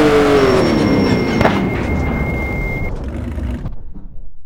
Index of /server/sound/vehicles/lwcars/porsche_911_rsr
slowdown_slow.wav